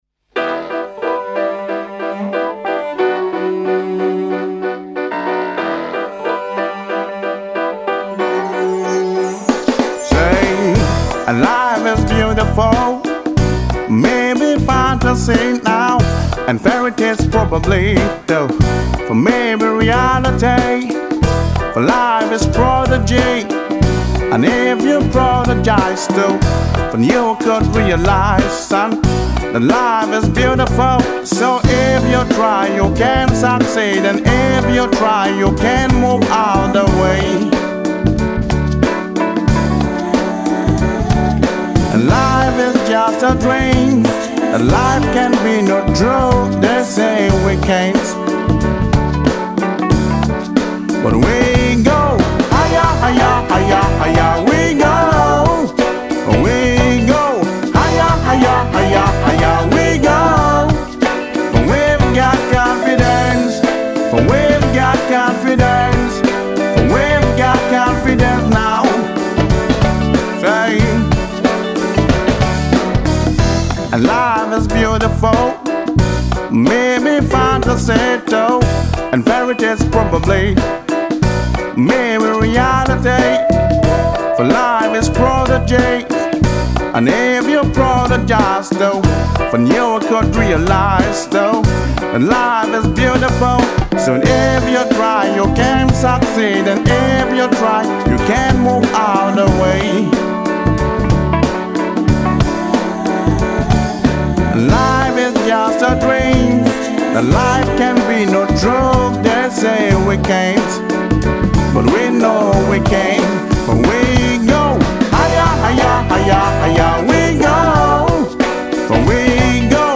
With Jazz and Afrobeat elements